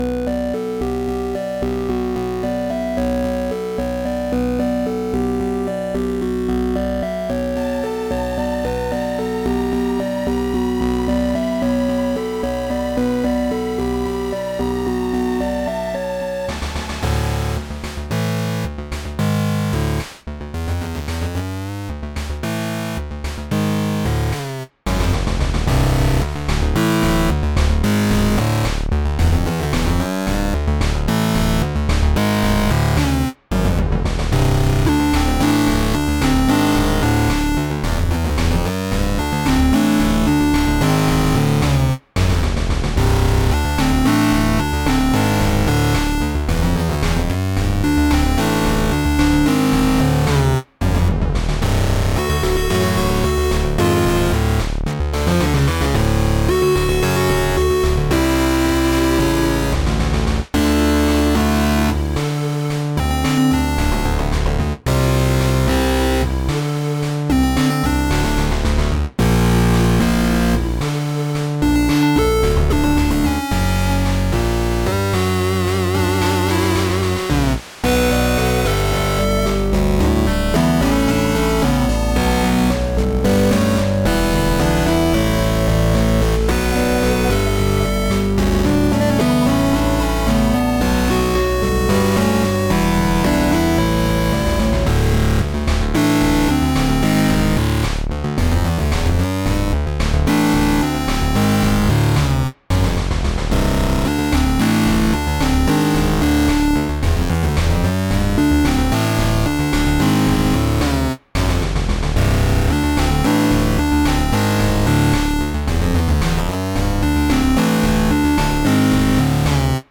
8-bit